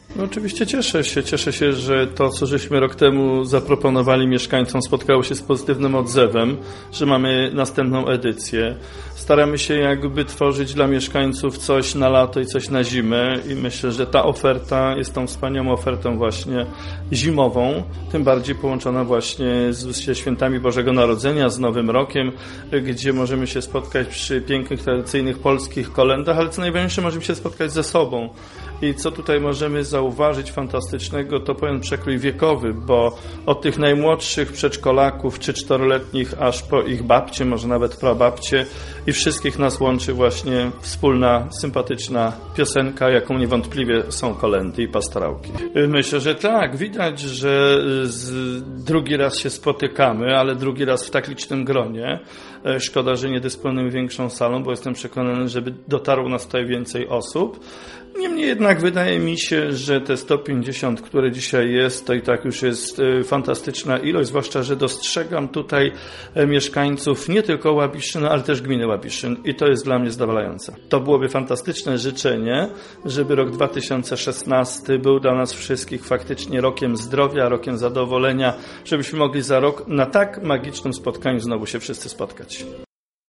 mówił Burmistrz Jacek Idzi Kaczmarek
1_burmistrz_idzi.mp3